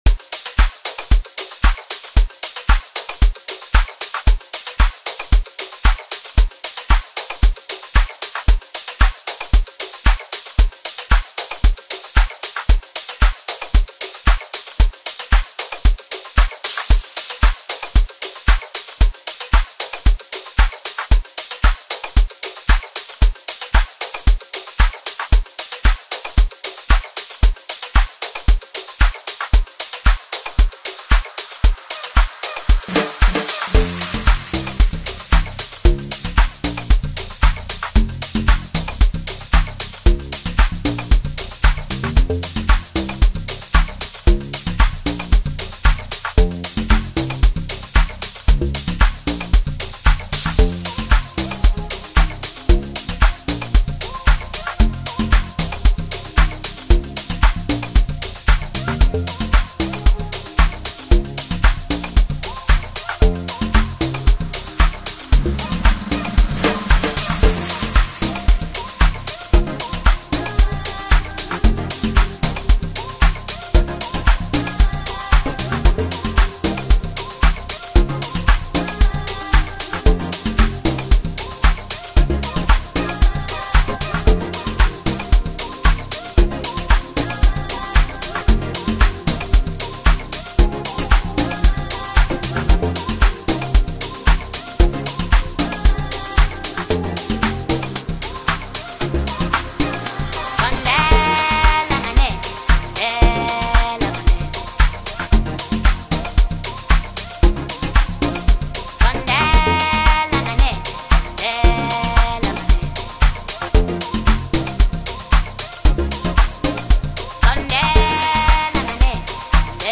Song Genre: Amapiano.